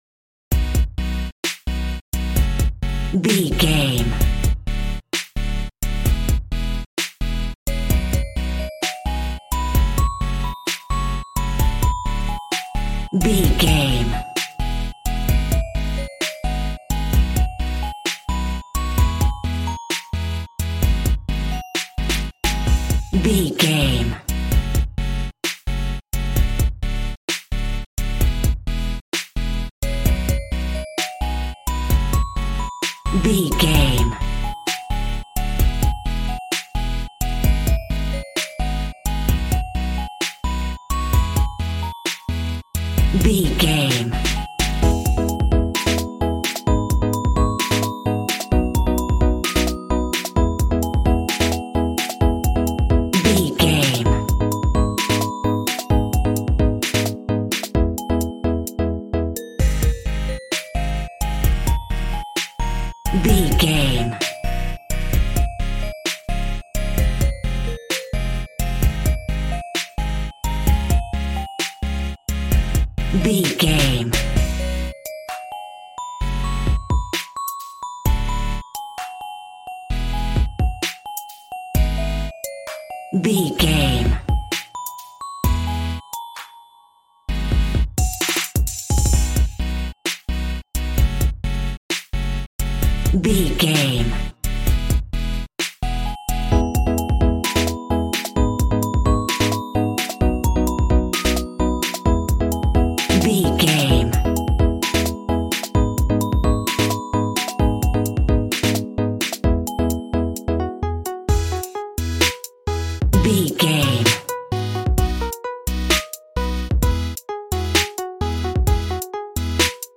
Ionian/Major
calm
smooth
synthesiser
piano